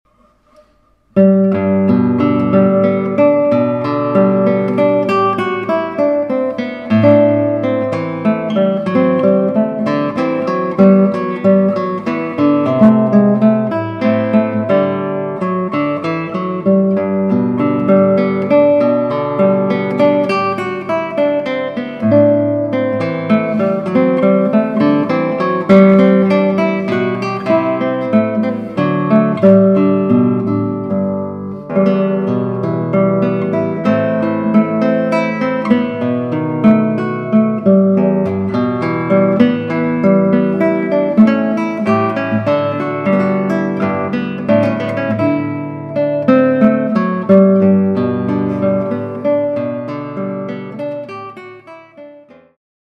Musik für Gitarre